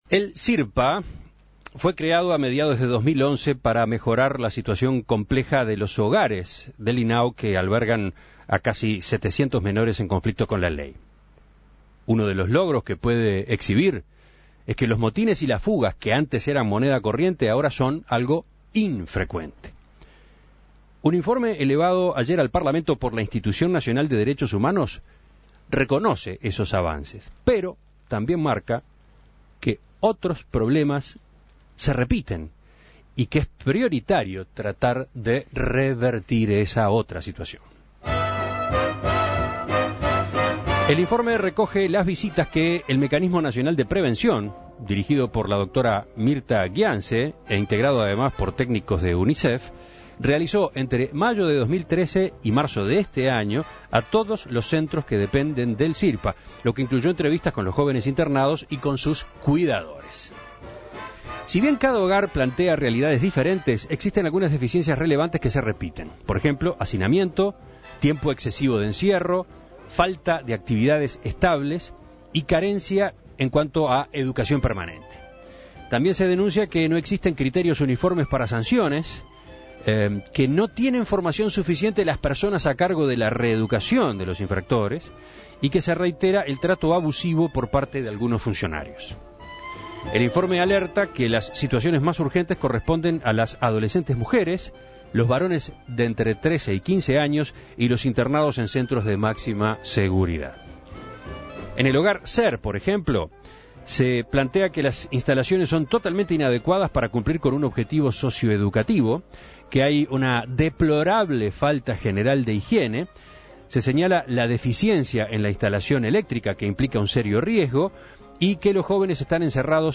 A propósito del contenido de este informe, En Perspectiva entrevistó al presidente del Sirpa, Ruben Villaverde.